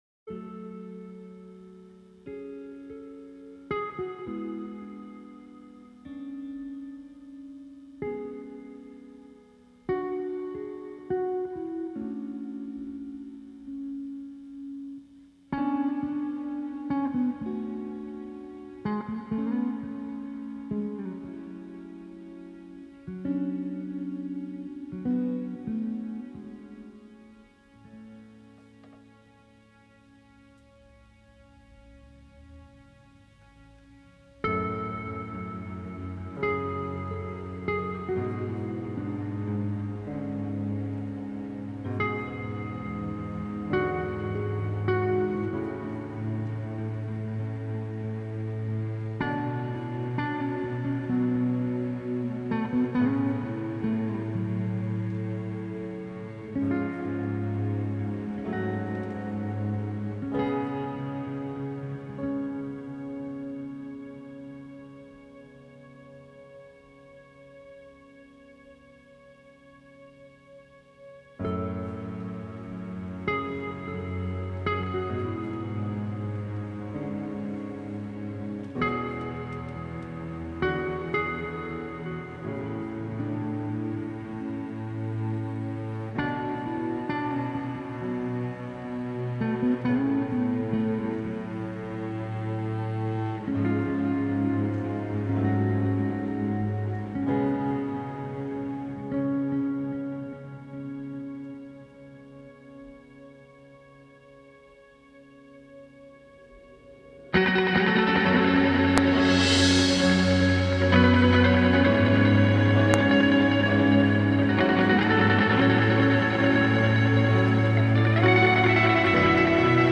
دقیقه ۱۱ تازه آهنگ به اوج می‌رسه.